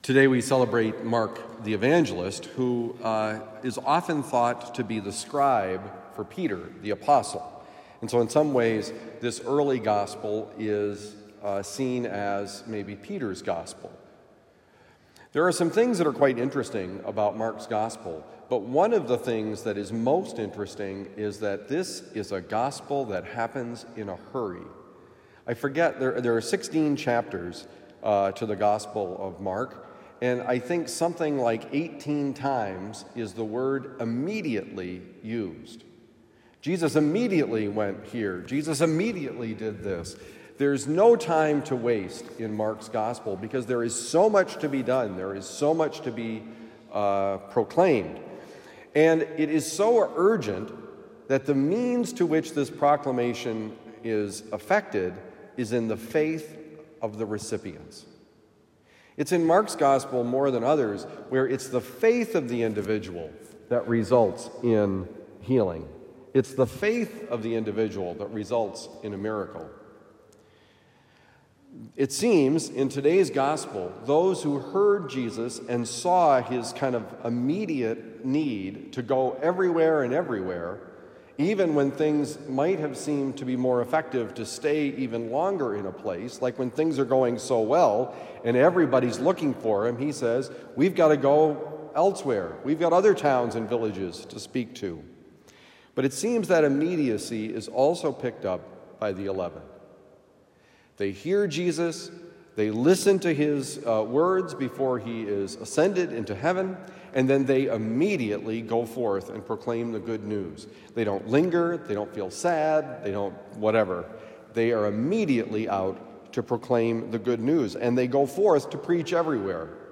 The Urgency of Sharing our Faith: Homily for Tuesday, April 25, 2023
Given at Christian Brothers College High School, Town and Country, Missouri.